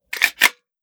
12ga Pump Shotgun - Load Shells 005.wav